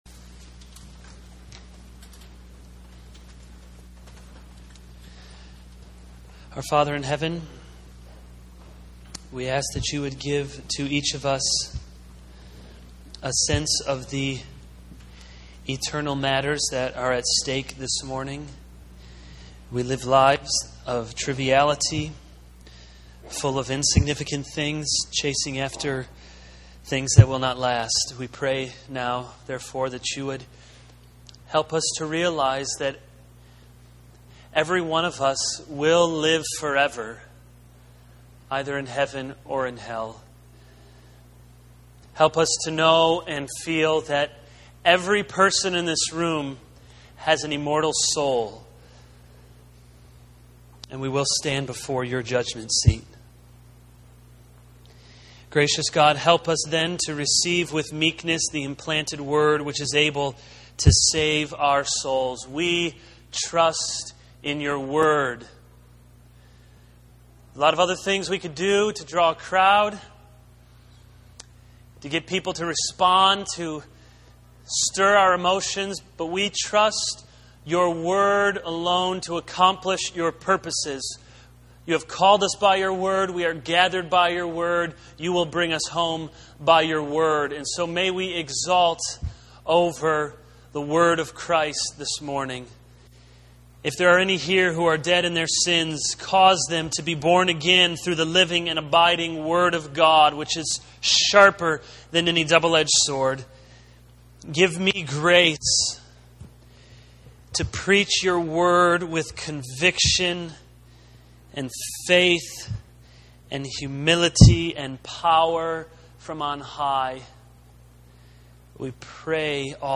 This is a sermon on 2 Corinthians 5:16-17.